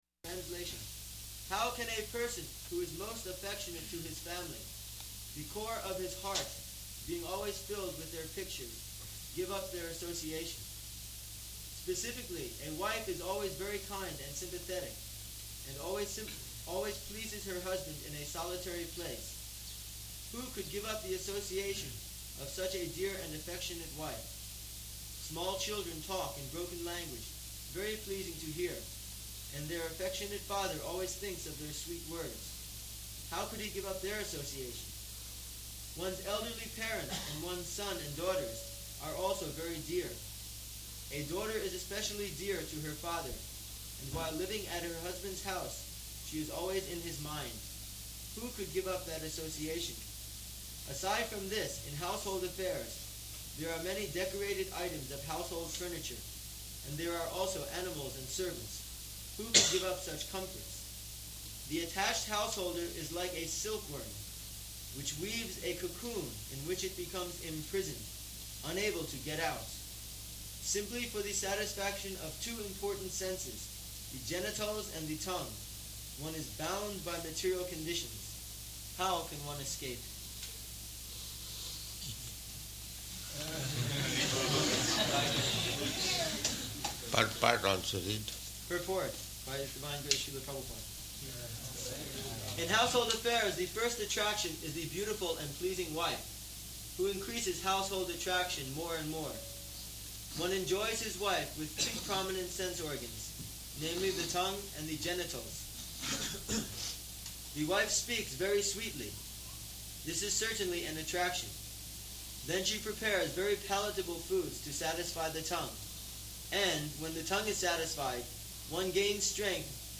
Location: New Vrindavan